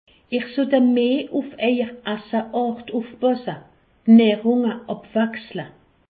Haut Rhin
Ville Prononciation 68
Pfastatt